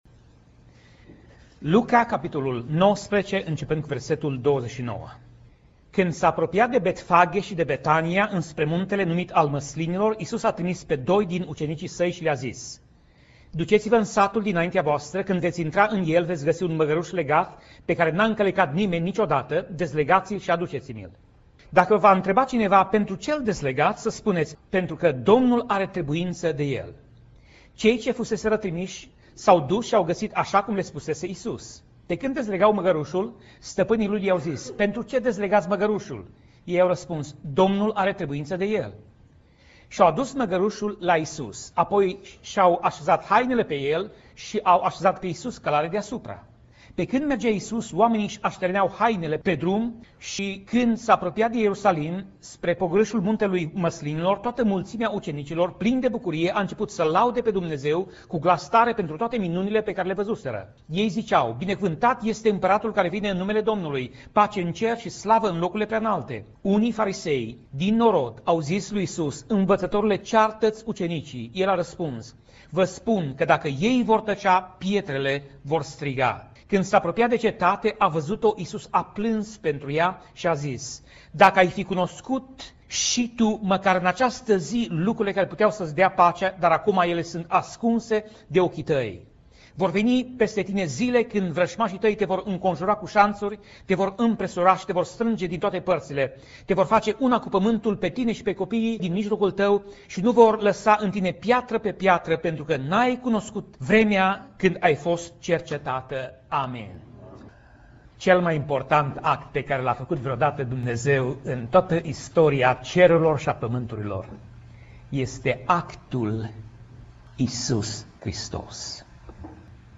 Pasaj Biblie: Luca 19:41 - Luca 19:44 Tip Mesaj: Predica